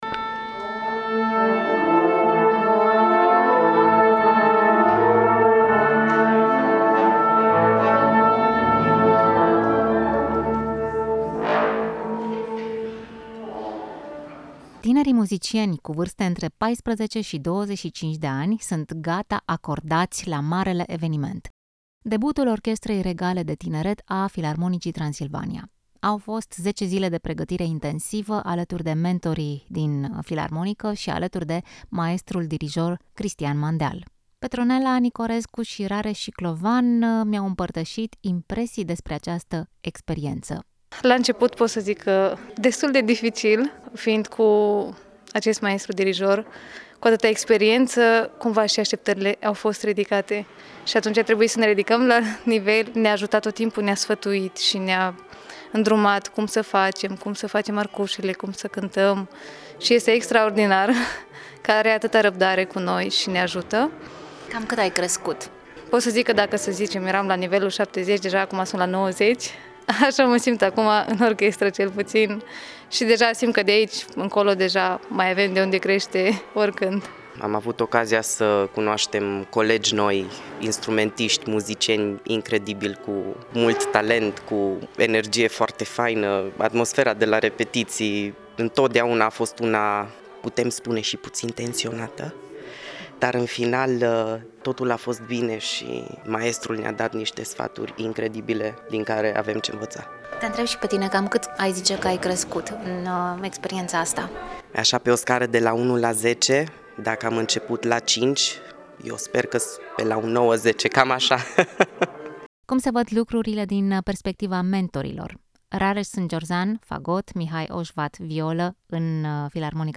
Am participat la repetițiile finale și am stat de vorbă cu câțiva dintre tineri și mentori